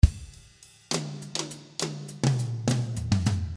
Drum beat in 102 bpm 6/8 time signature.
This drum beat is at 102 bpm in 6/8 time signature.
A lot of cymbals and 8 different drum fills .